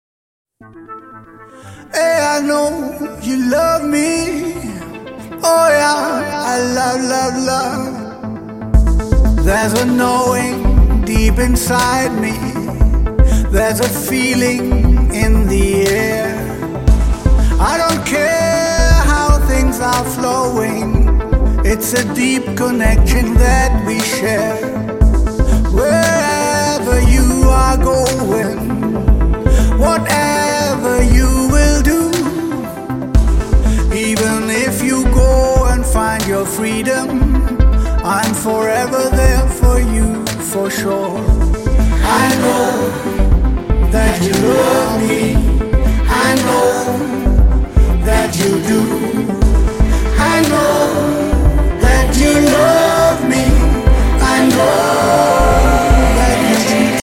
European Pop
with with a slight African taste